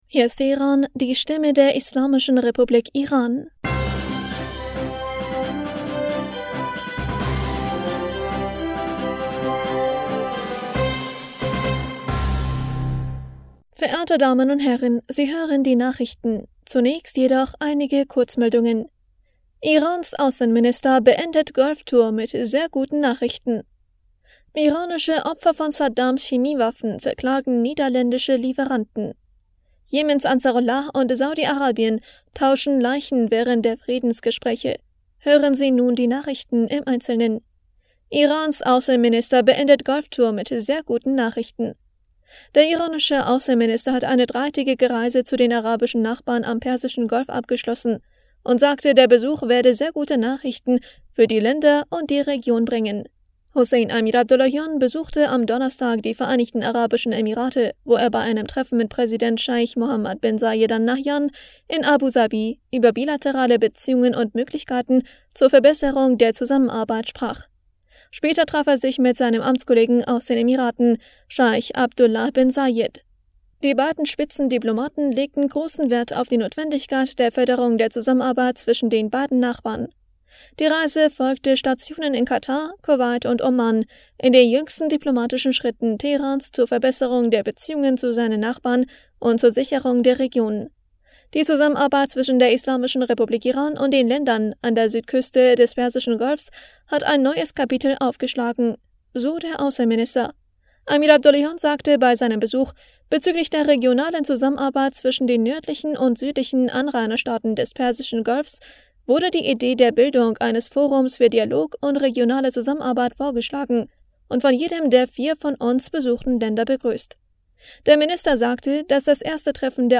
Nachrichten vom 23. Juni 2023